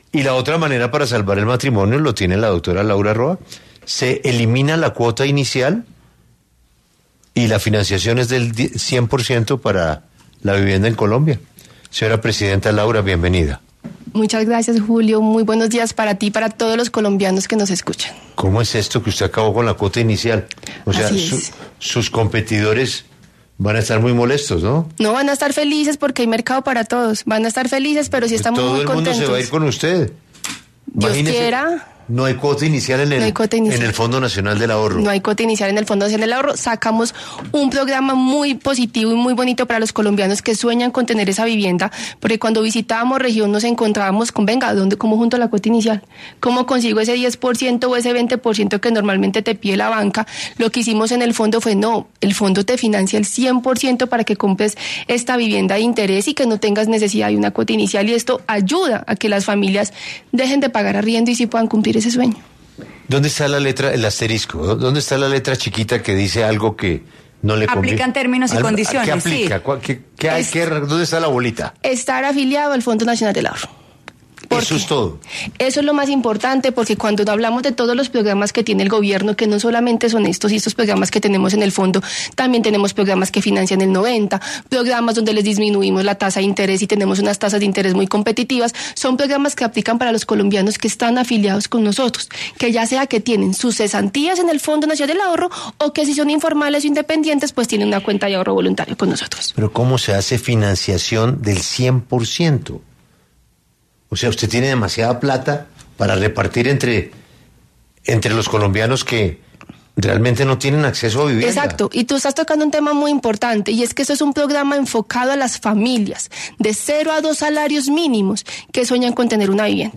Laura Roa, presidenta del Fondo Nacional del Ahorro, habló en 6AM W y dio detalles de los requisitos y cómo acceder a estos beneficios de la entidad